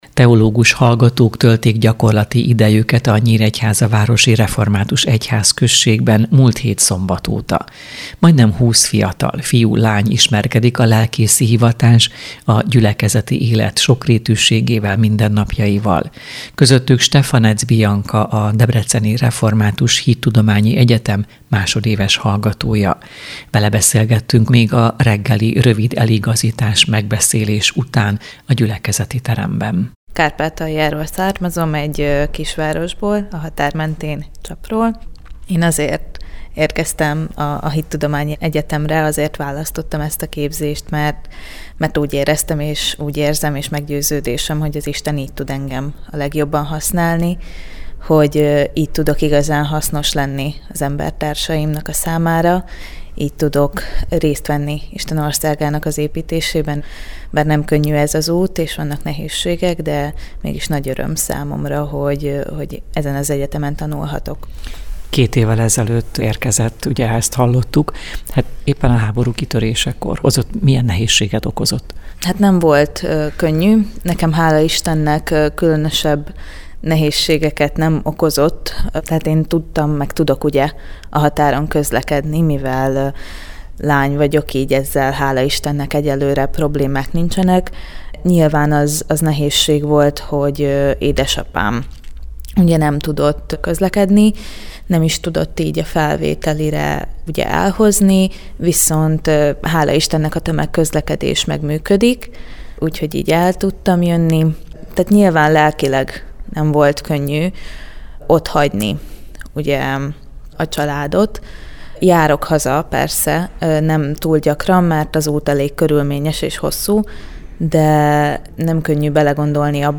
Vele beszélgettünk a reggeli rövid eligazítás-megbeszélés után a gyülekezeti teremben.